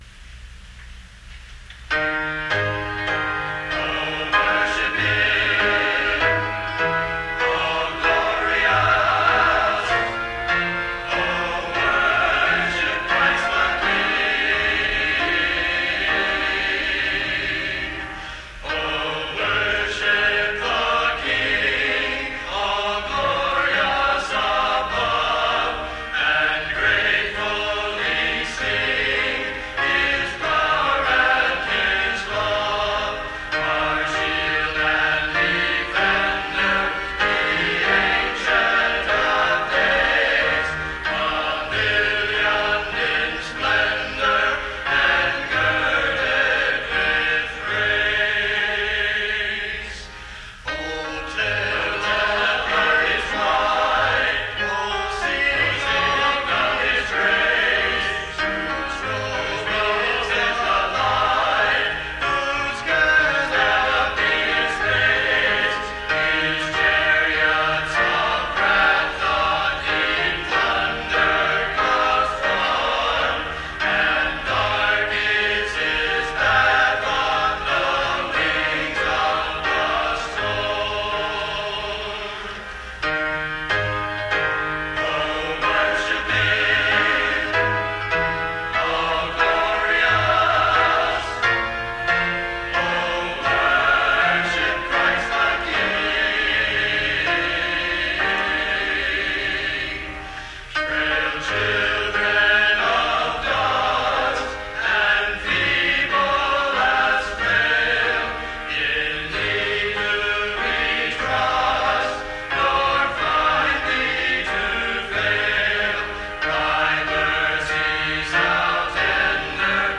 Men's Choir - New Leaves
As men gather together and lift their voices in praise to God, I believe that our Lord is pleased.